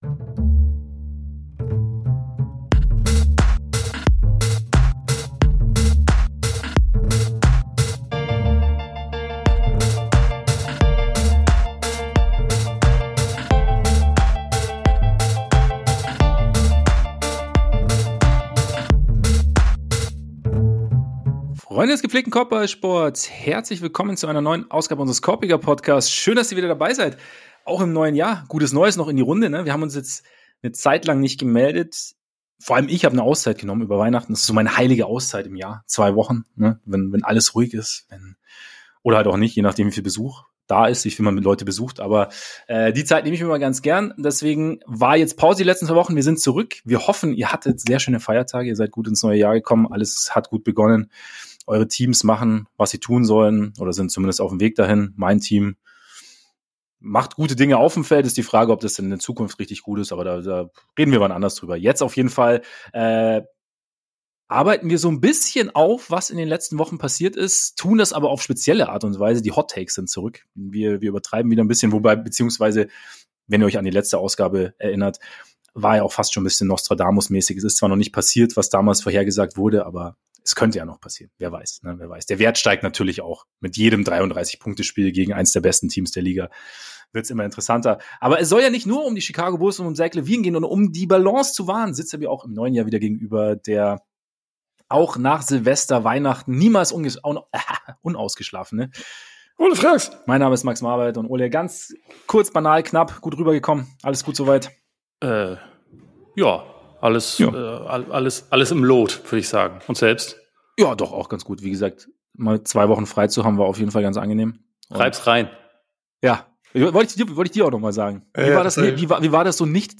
All das und vieles, vieles mehr beantwortet der neue Livestream!